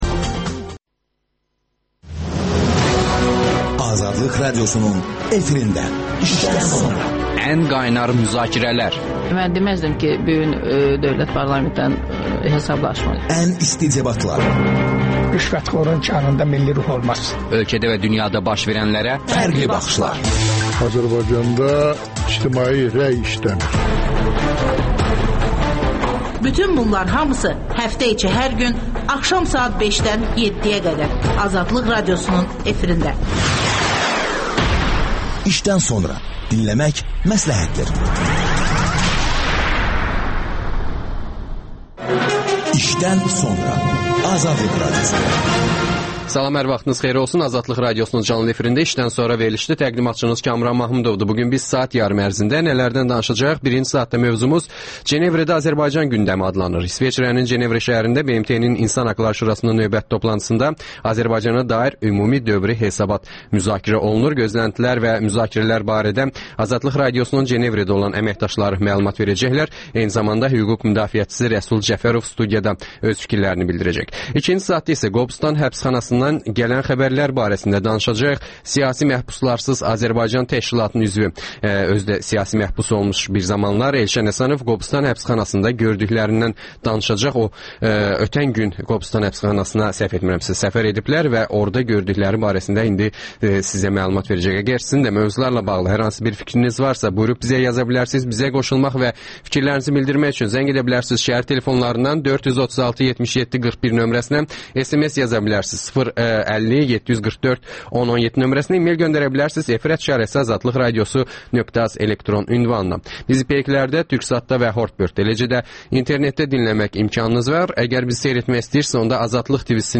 İsveçrənin Cenevrə şəhərində BMT-nin İnsan Haqları Şurasının növbəti toplantısında Azərbaycana dair Ümumi Dövri Hesabat müzakirə olunur. Gözləntilər və müzakirələr barədə AzadlıqRadiosunun Cenevrədə olan əməkdaşları məlumat verirlər.